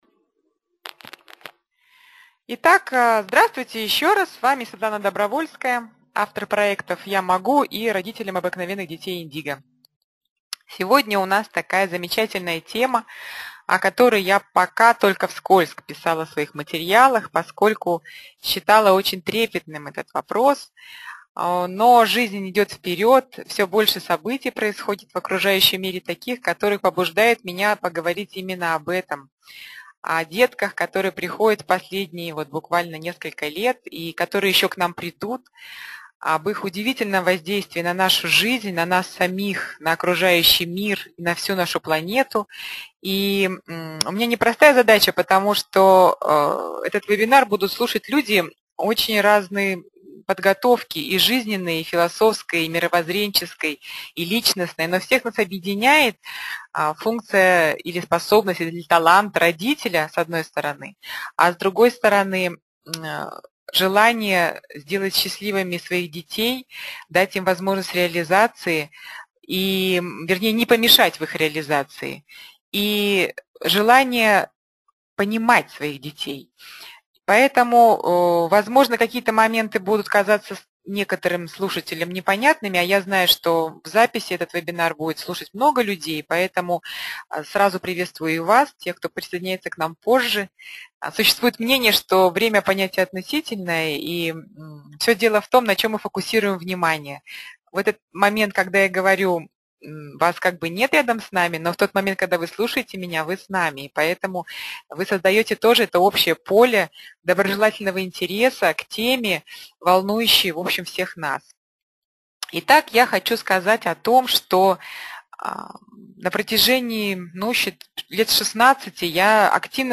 На вебинаре были рассмотрены следующие вопросы: Встреча в Москве 18 января 2026 по 15 ноября 2025 спецпредложение Подробнее -> Анонс авторской встречи 1.